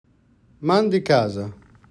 To hear the pronunciation of MandiCasa, click here
MandiCasa-pronunciation-1.wav